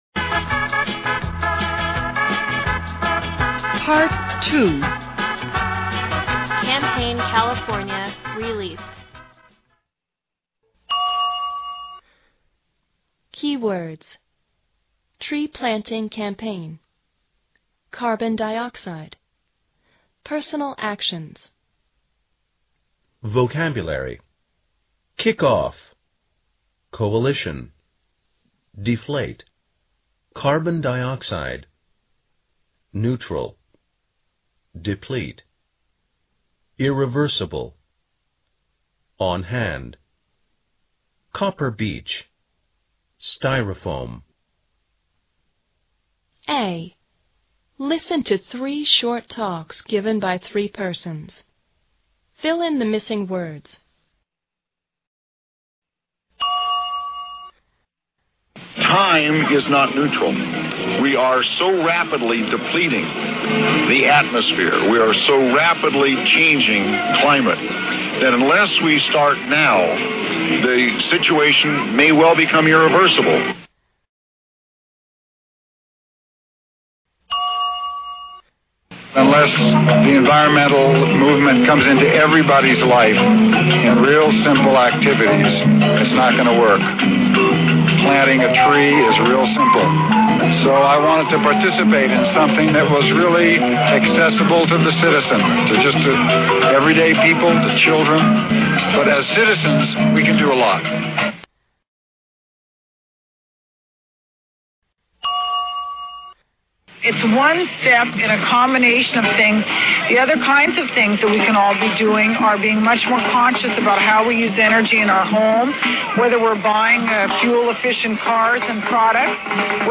A. Listen to three short talks given by three persons.